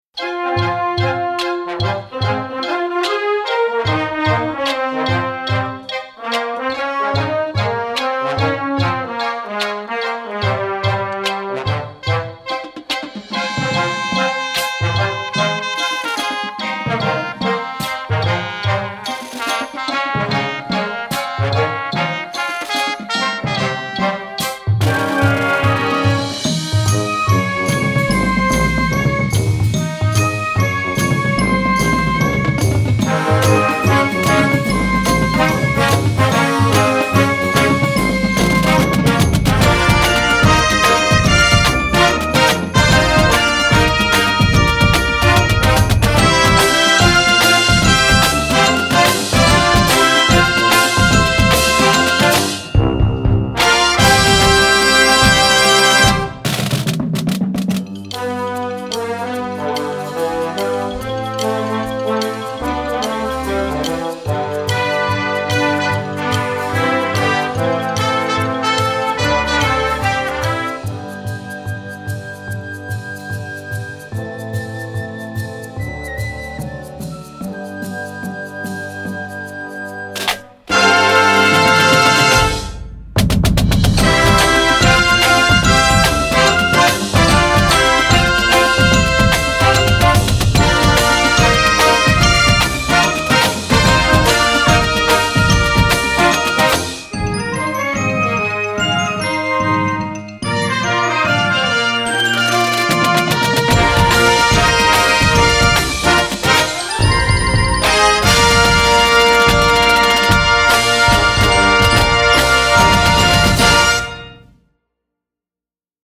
Reihe/Serie: Marching-Band
Besetzung: Blasorchester